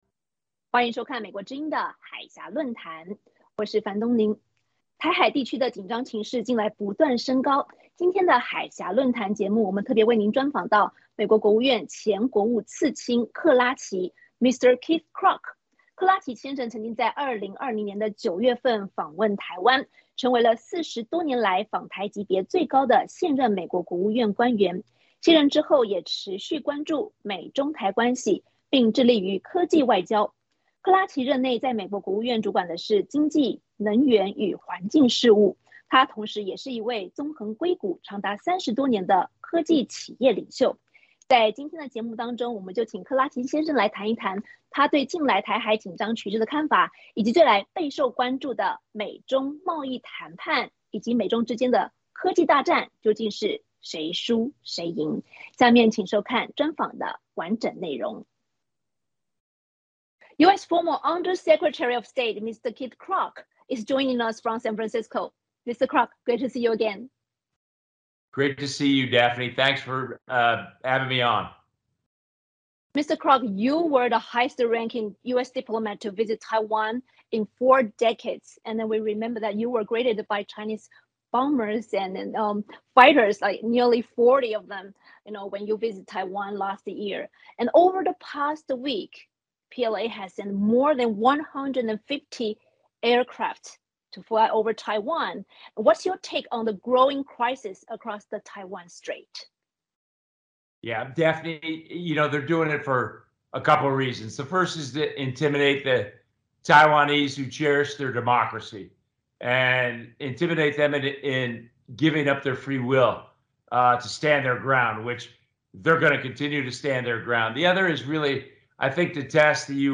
海峡论谈：专访前国务次卿克拉奇：解放军攻台将导致习近平政治灭亡
台海地区的紧张情势近来不断升高，美国总统拜登承诺将在中共进犯时协防台湾引发各界关注。海峡论谈节目特别为您专访前美国国务次卿克拉奇(Mr. Keith Krach)。